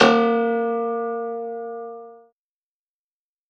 53v-pno02-A1.wav